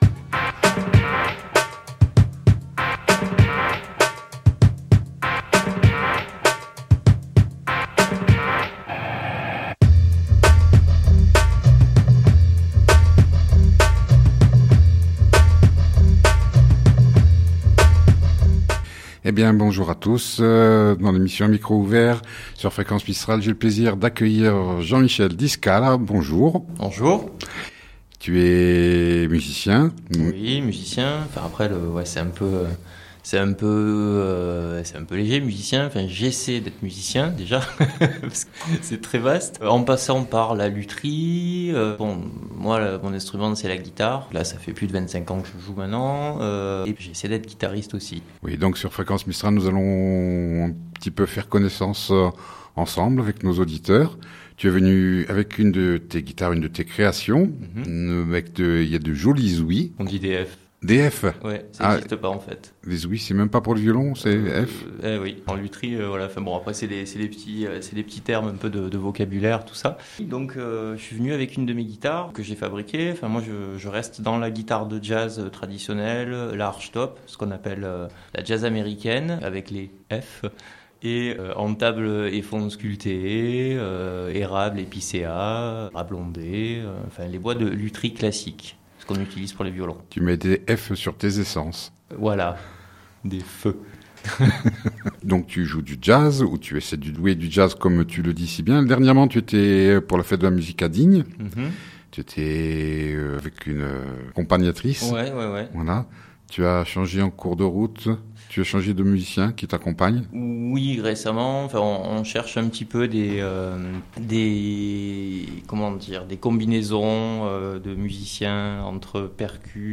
Lors de son passage dans nos studios à Digne